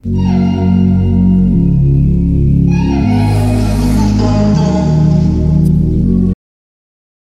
Vox pads